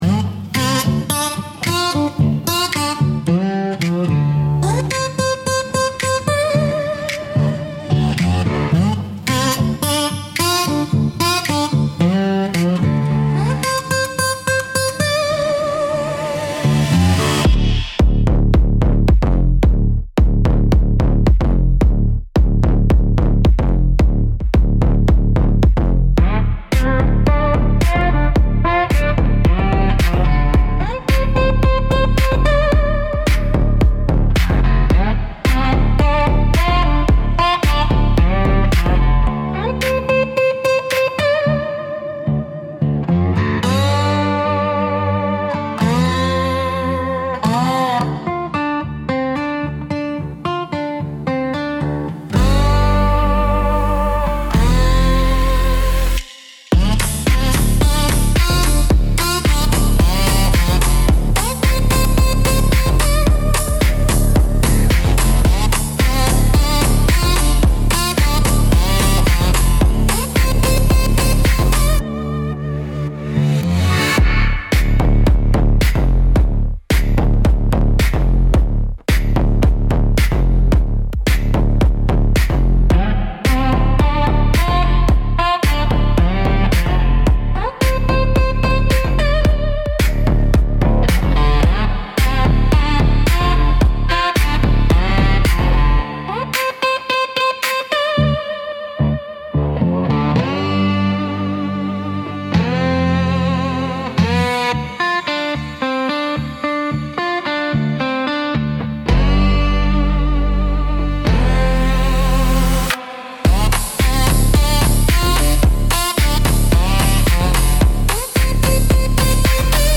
Instrumental - Bayou Bells